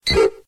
Cri de Roucool dans Pokémon X et Y.